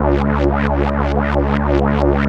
3100 AP  C#3.wav